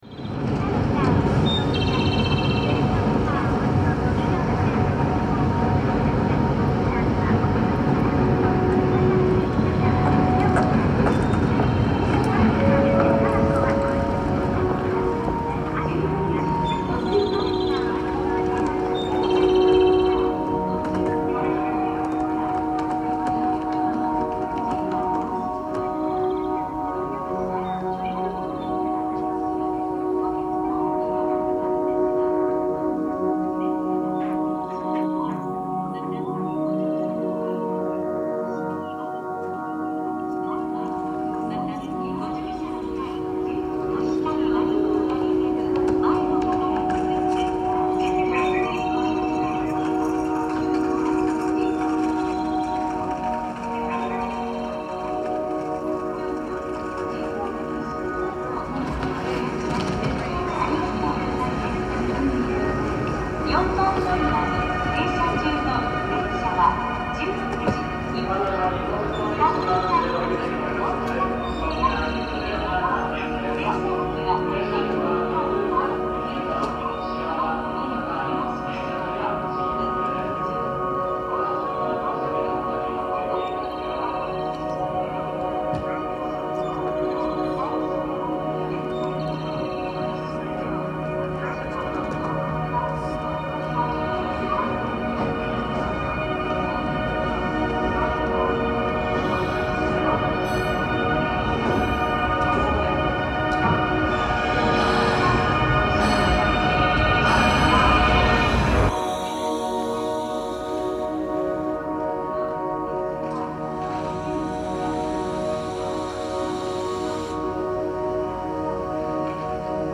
Kyoto railway station walkway reimagined